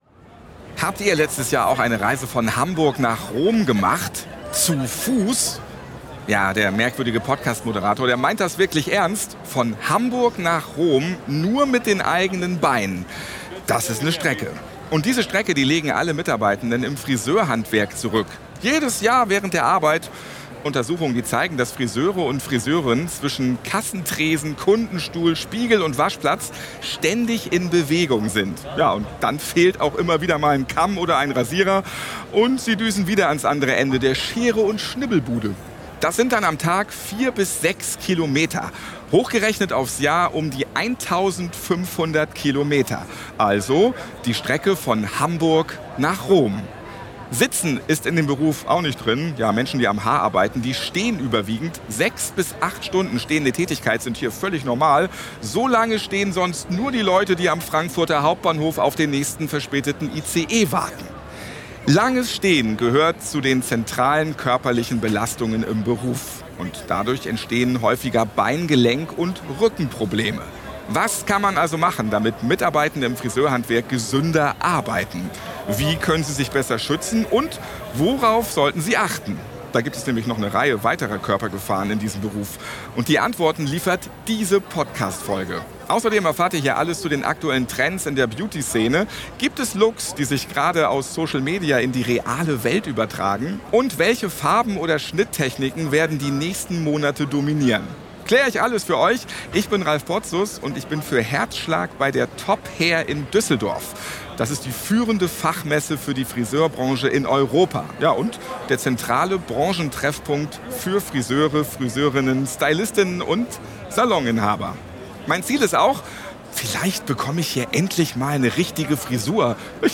Vor Ort spricht er mit Expertinnen und Experten aus der Branche über aktuelle Haartrends, nachhaltige Colorationen, Pflege- und Stylingroutinen und vor allem über die gesundheitlichen Belastungen im Friseurhandwerk. Im Fokus stehen die Themen Hautschutz, Ergonomie, Lärm, langes Stehen und der Umgang mit Chemikalien.